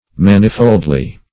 manifoldly - definition of manifoldly - synonyms, pronunciation, spelling from Free Dictionary Search Result for " manifoldly" : The Collaborative International Dictionary of English v.0.48: Manifoldly \Man"i*fold`ly\, adv.